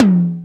DX Tom 01.wav